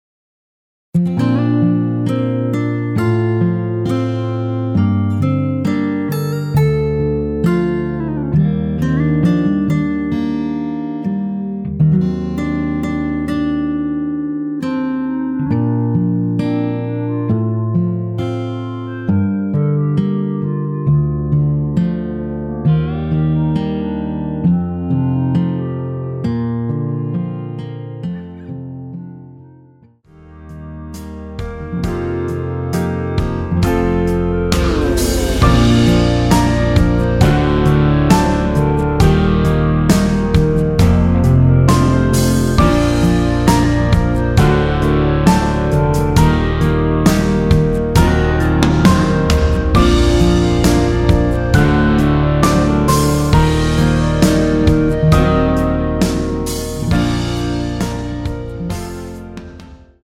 원키에서(-2) 내린 멜로디 포함된 MR 입니다.
Bb
앞부분30초, 뒷부분30초씩 편집해서 올려 드리고 있습니다.
중간에 음이 끈어지고 다시 나오는 이유는